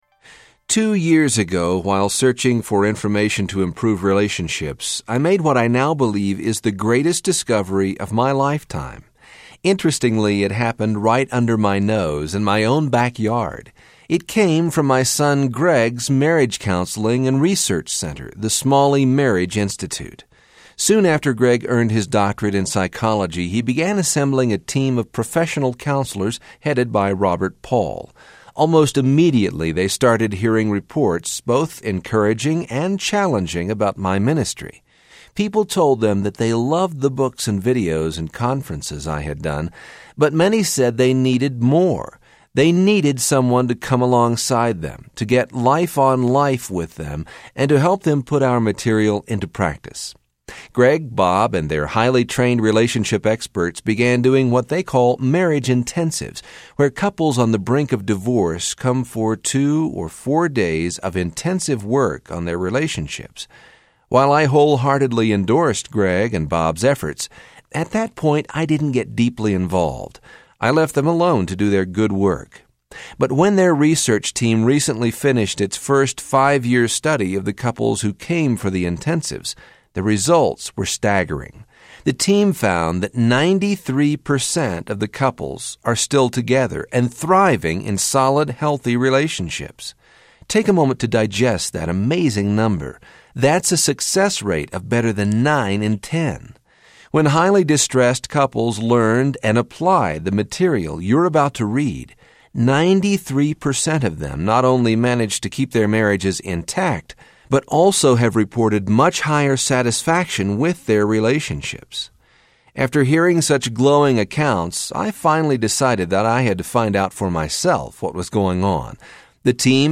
The DNA Of Relationships Audiobook
Narrator
7.25 Hrs. – Unabridged